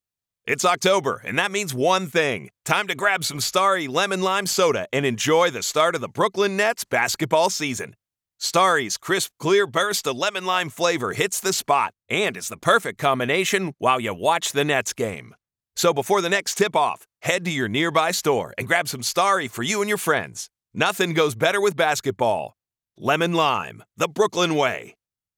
Professional American male voice. Conversational, guy next door, instructional, strong and occasionally snarky!
Starry Lemon Lime Soda spot for Brooklyn Nights campaign
Middle Aged